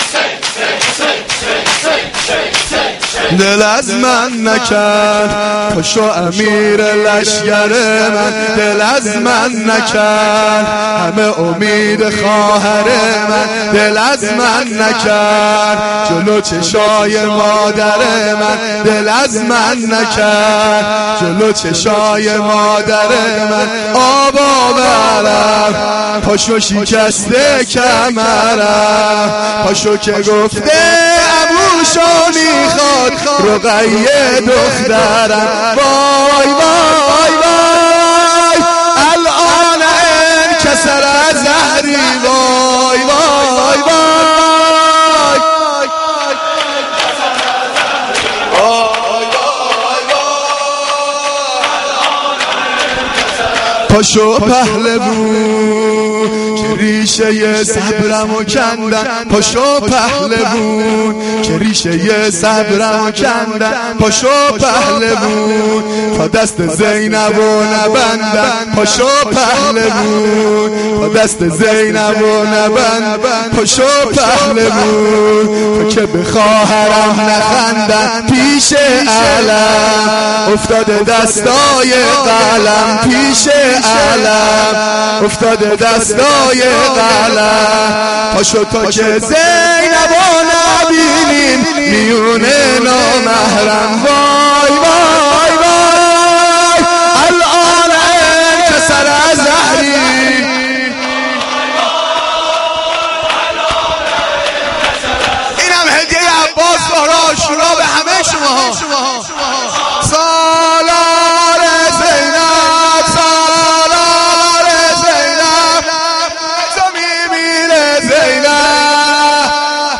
مداحی
Roz-9-Moharam-6.mp3